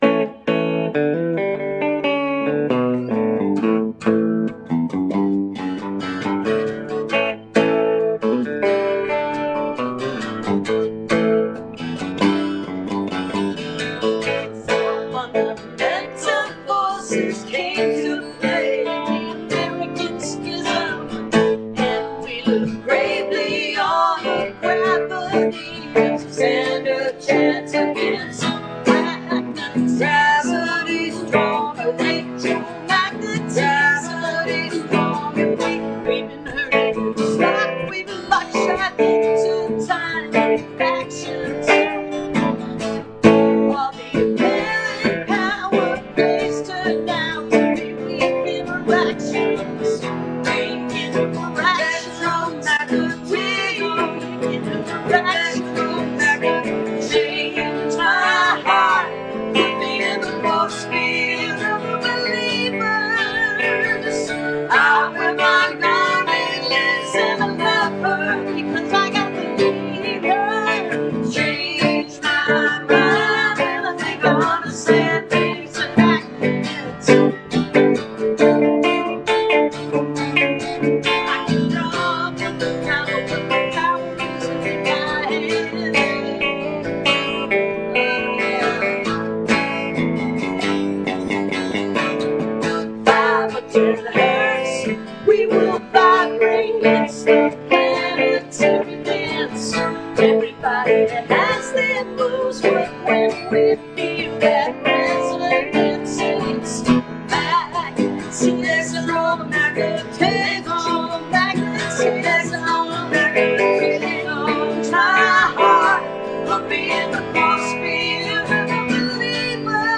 (captured from facebook)